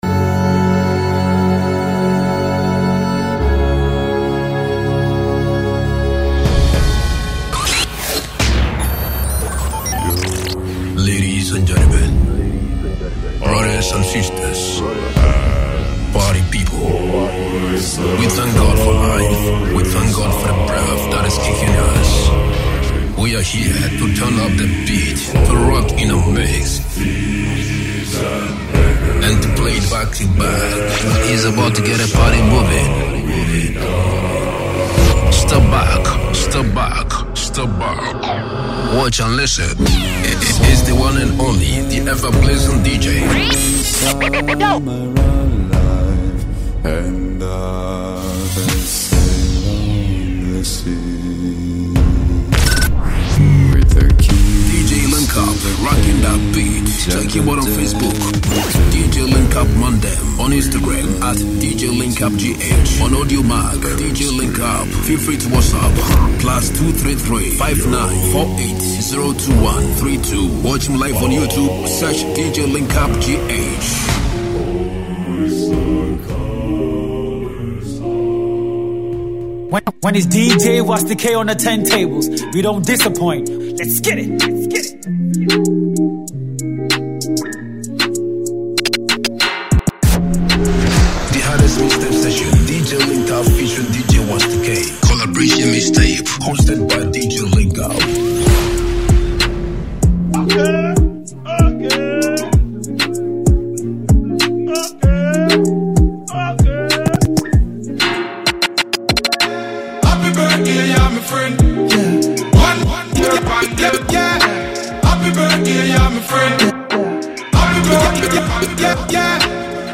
with its infectious sounds and addictive rhythms.